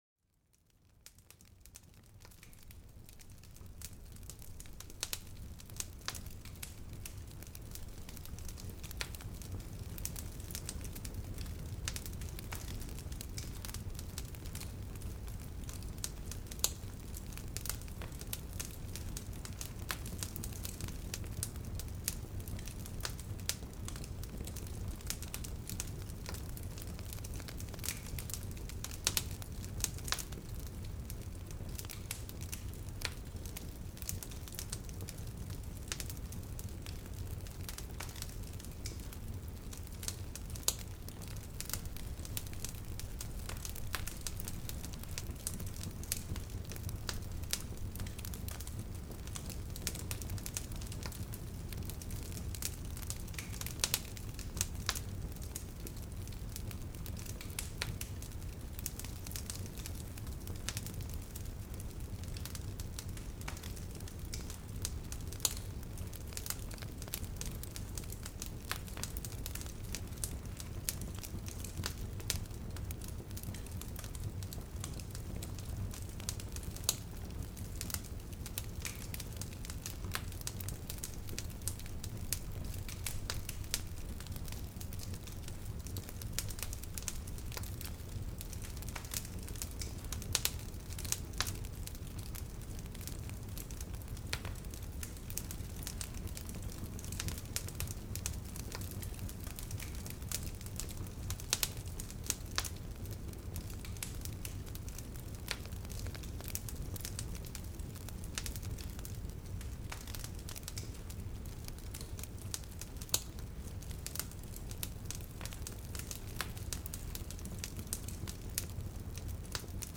En este episodio, nos sumergimos en el sonido de los chisporroteos y murmullos del fuego. Cada chisporroteo te transporta cerca de una fogata reconfortante, alejando el estrés del día.